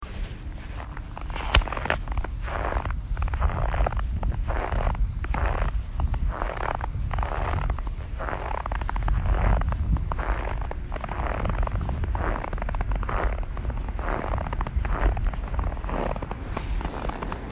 描述：慢慢地走在非常干燥的雪地里
Tag: 冬天 足迹 行走